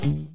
1 channel
virgin-launch-stop.mp3